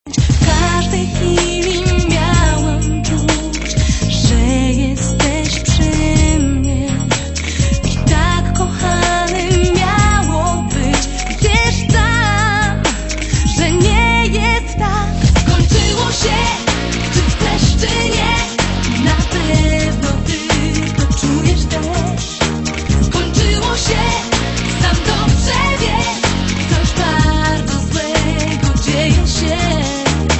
album version,
gitara elektryczna
gitara basowa
instr. klawiszowe, programowanie, rhodes
ewi
chórki